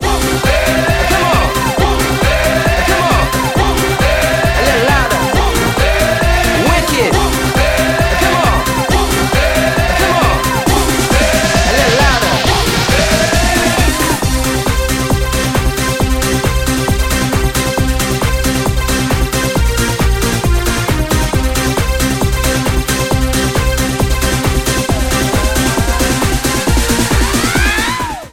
евродэнс